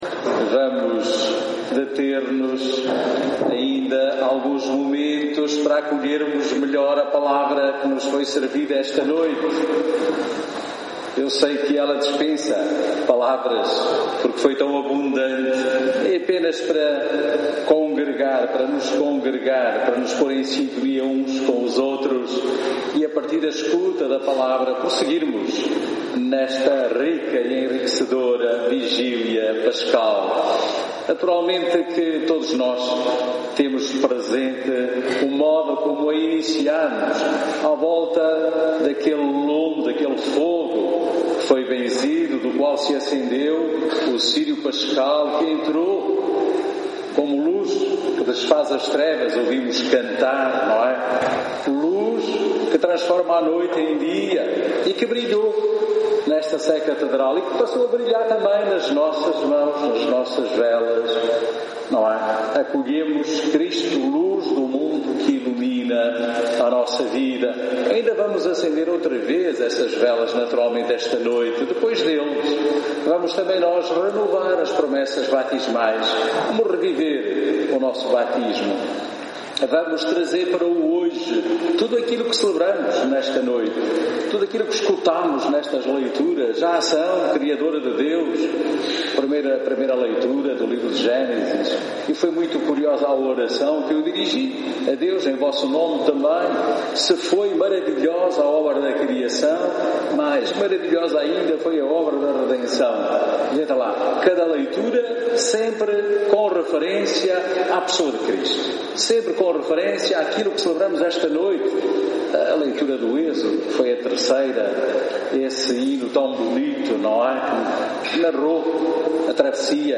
Homilia_vigilia_pascal_2017.mp3